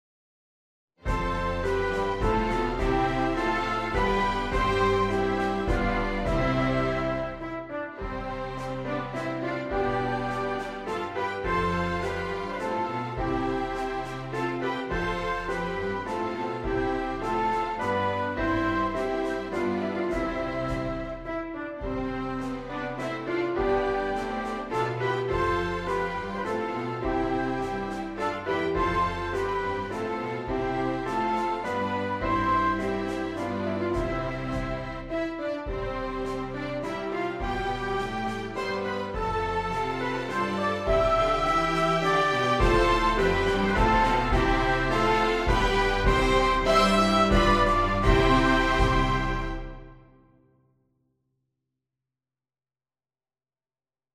traditional arrangement